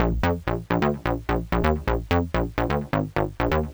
Eurofissure Bass Bb 128.wav